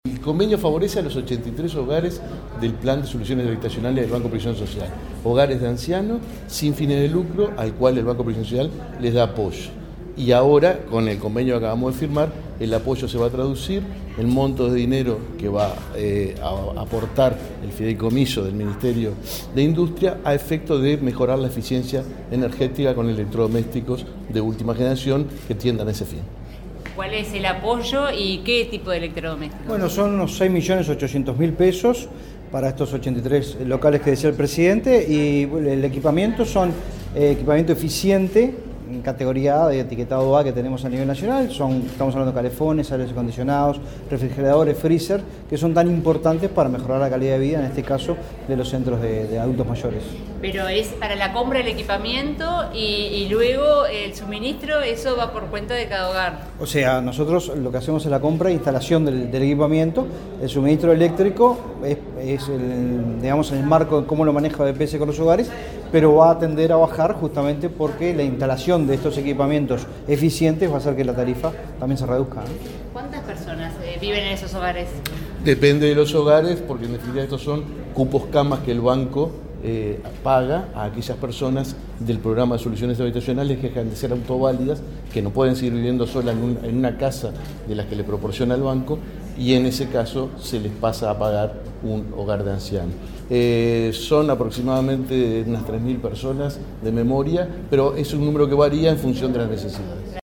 Declaraciones del presidente del BPS y del director nacional de Energía
El Ministerio de Industria, Energía y Minería, el Banco de Previsión Social (BPS) y la Corporación Nacional para el Desarrollo (CND) firmaron un convenio que permitirá equipar hogares de ancianos sin fines de lucro con electrodomésticos eficientes categoría A. El presidente del BPS, Alfredo Cabrera, y el director nacional de Energía, Fitzgerald Cantero, informaron a la prensa sobre el alcance del acuerdo.